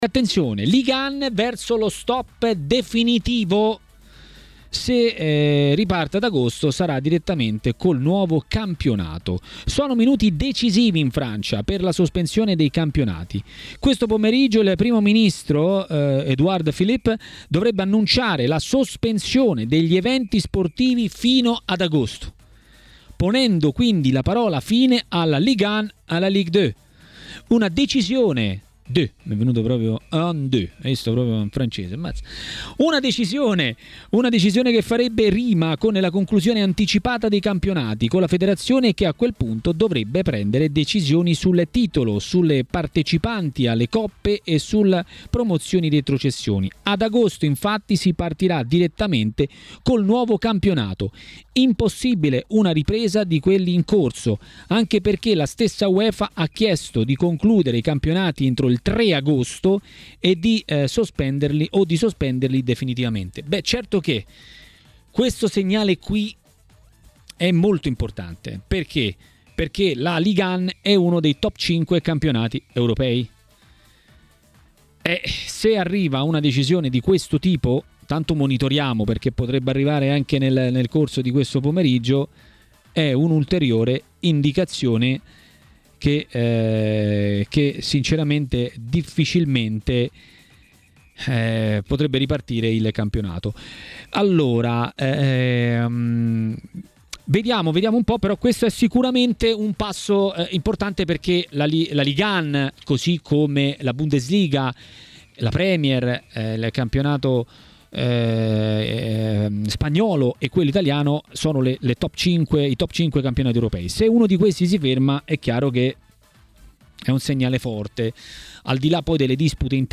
Mister Gianni Di Marzio ha commentato a Maracanà, nel pomeriggio di TMW Radio, le notizie principali delle giornata.